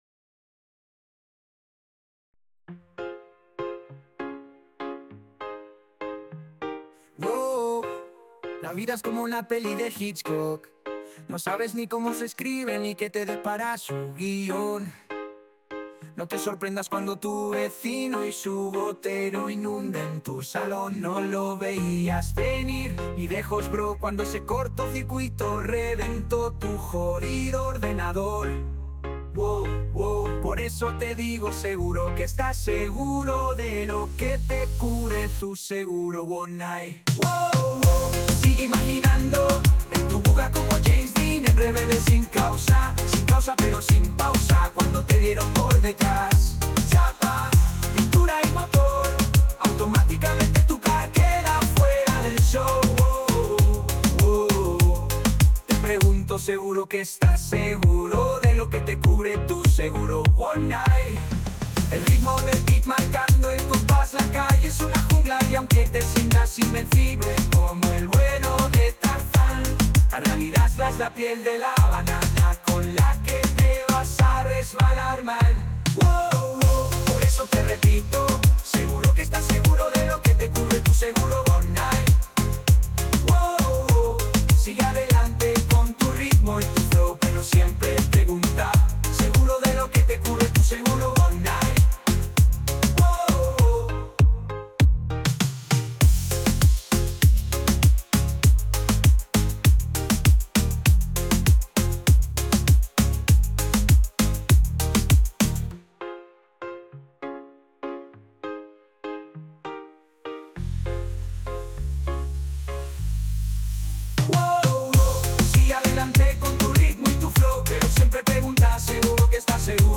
pegadiza no, lo siguiente.